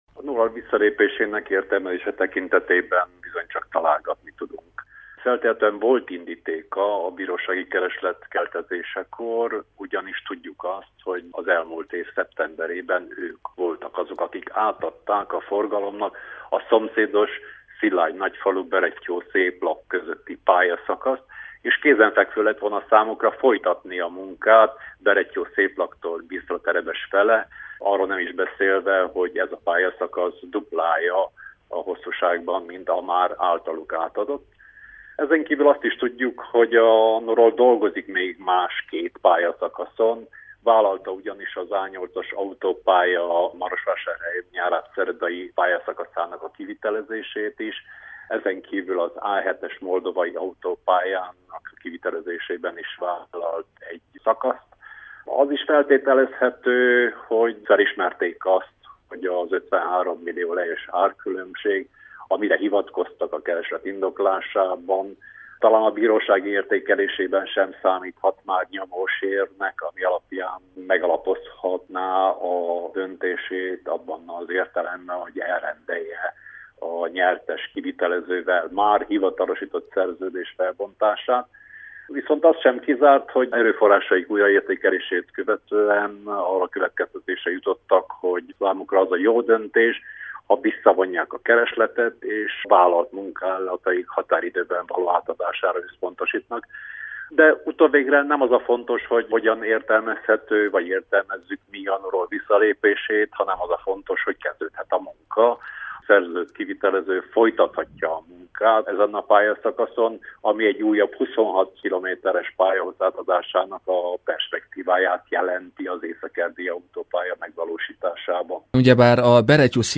A Bisztraterebes – Berettyószéplak sztrádaszakasz építéséről Sándor Gábor volt államtitkárral, közúti forgalombiztonsági szakértővel beszélgettünk.